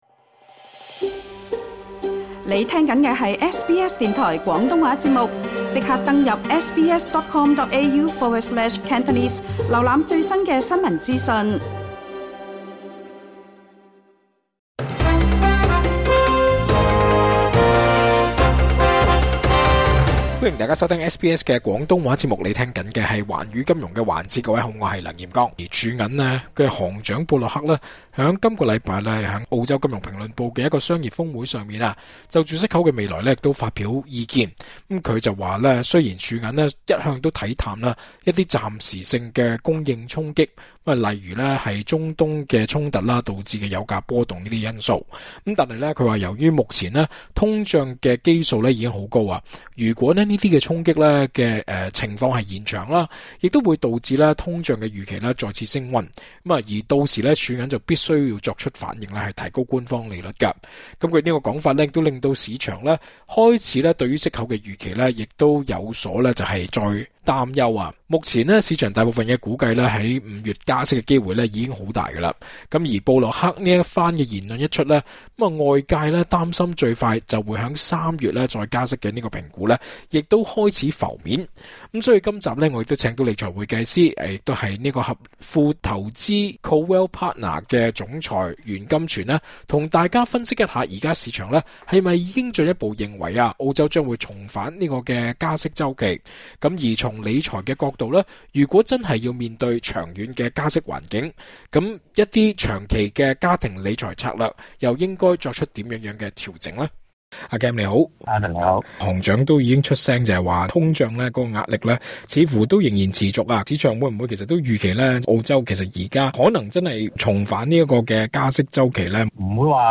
詳盡訪問： LISTEN TO 【點部署？